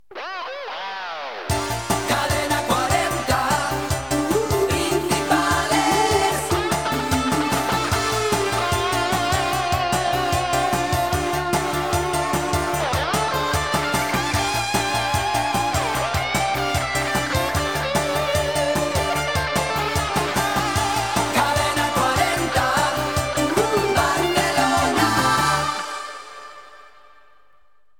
Indicatiu de l'emissora
FM